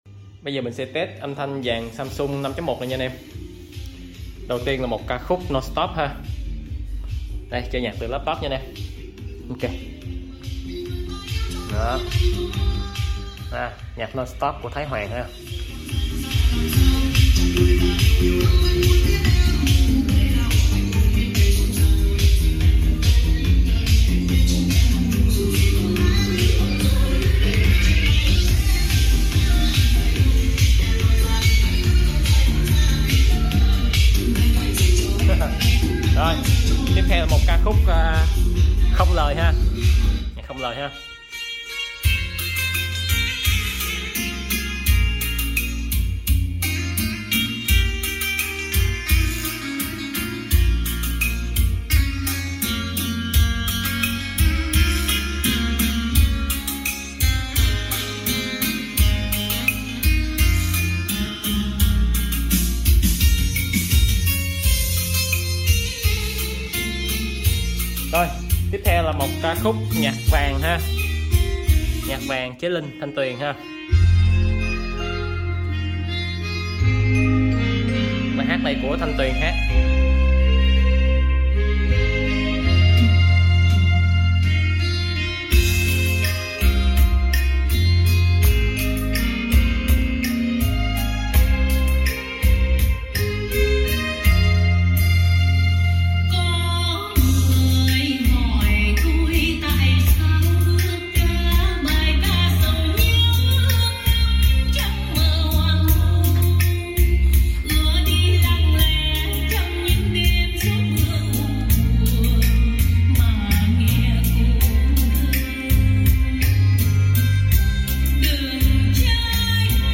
Test âm thanh dàn SAMSUNG 5.1